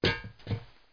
metalshuffle2.mp3